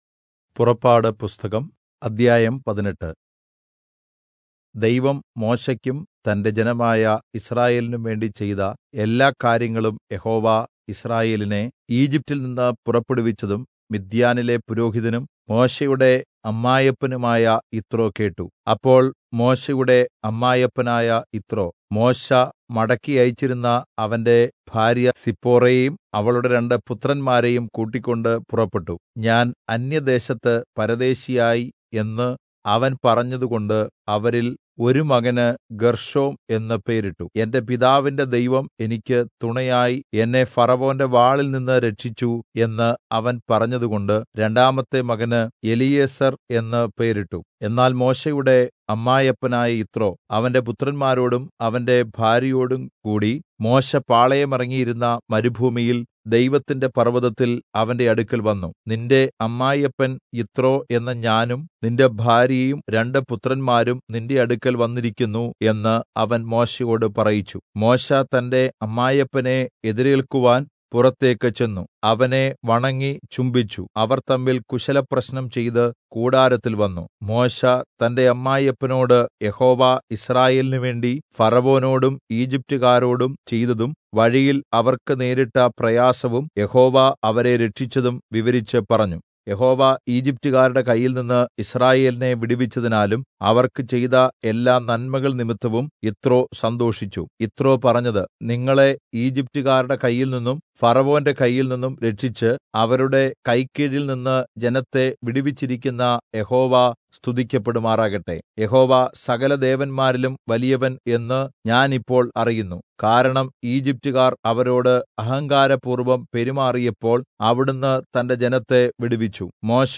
Malayalam Audio Bible - Exodus 39 in Irvml bible version